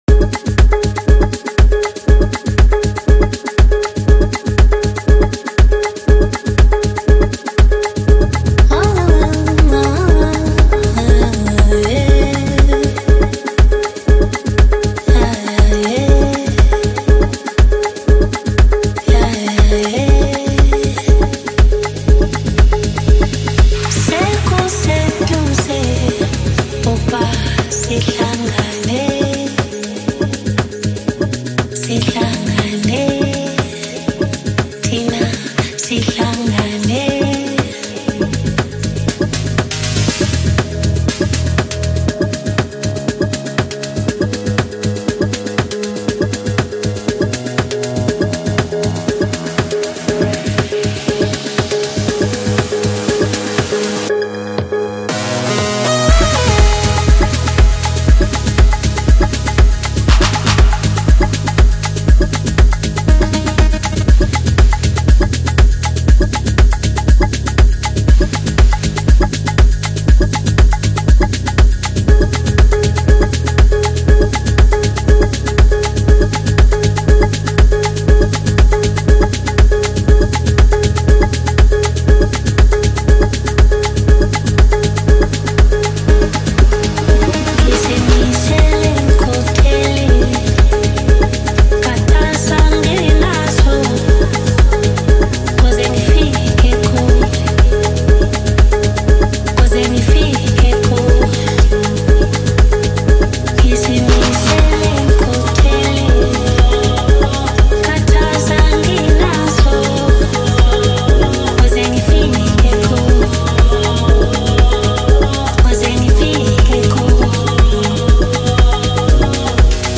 catchy rhythm, smooth vibe